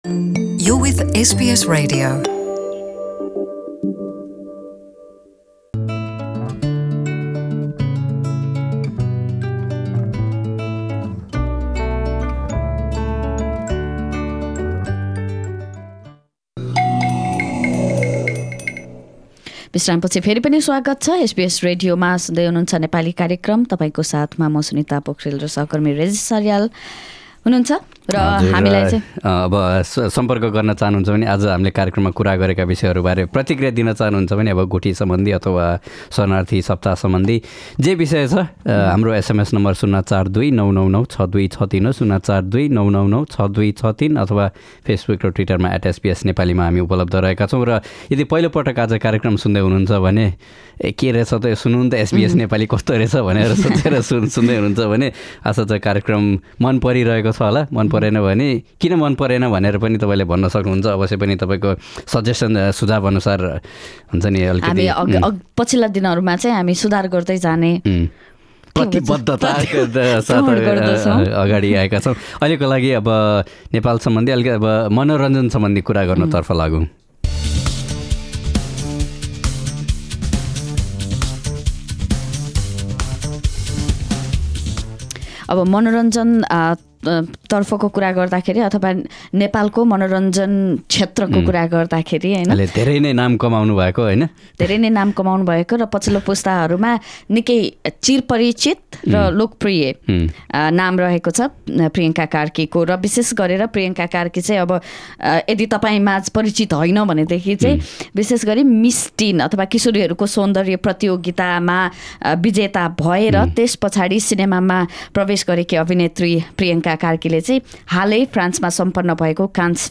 किशोरीहरूको सौन्दर्य प्रतियोगिता हुँदै सिनेमामा प्रवेश गरेकी अभिनेत्री प्रियङ्का कार्कीले हालै फ्रान्समा सम्पन्न भएको कान्स फिल्म्स फेस्टिभलमा सहभागिता जनाएकी थिइन्। अभिनेत्री कार्कीसँग हामीले उनको पेसा, भविष्यका सपना र उनले सुरु गरेका समाजसेवाका काम बारे गरेको कुराकानी।